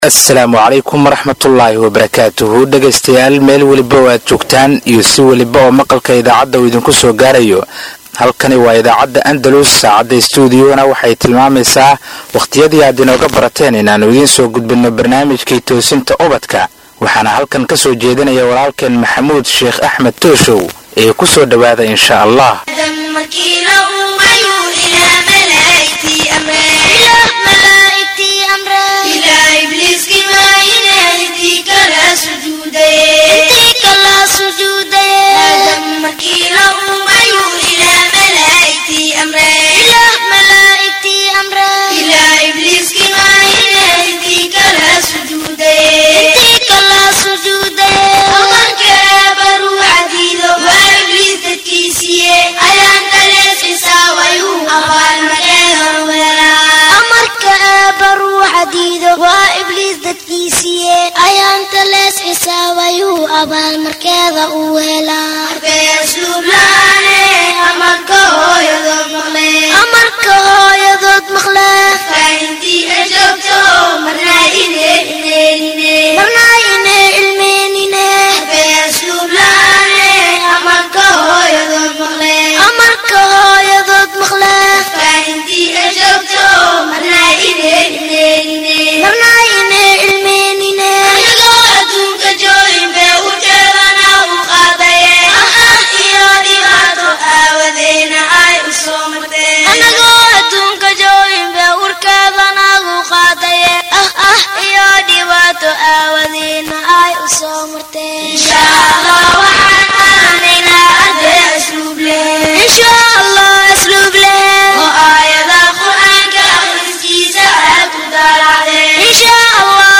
Halkan waxad ka dhagaysan kartaa barnaamijka todobaadlaha ah ee Toosinta Ubadka kaasi oo ka baxa idaacadda Andalus, barnaamijkan oo ah barnaamij ay caruurtu aad u xiisayso wuxuu ka koobanyahay dhowr xubnood oo kala ah wicitaanka iyo bandhiga caruurta, jawaabta Jimcaha, Iftiiminta qalbiga iyo xubinta su aasha toddobaadka.